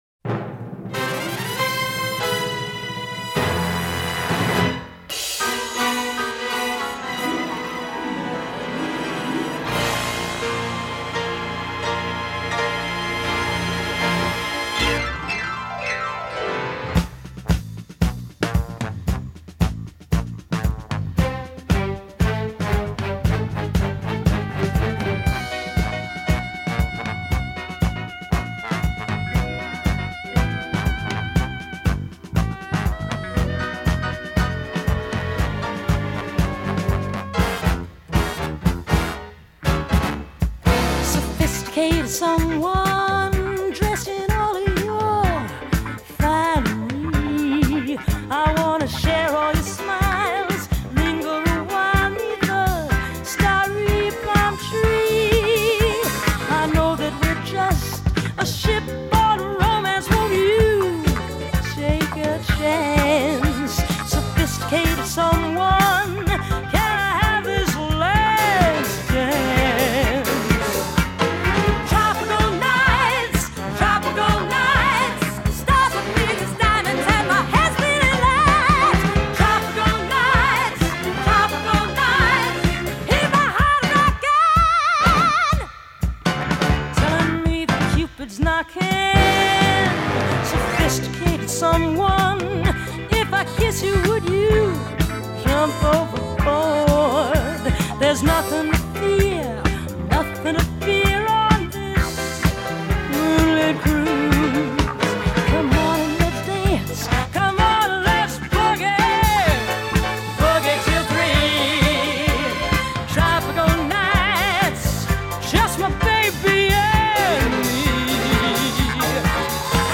begins atmospherically enough
it’s that caffeinated.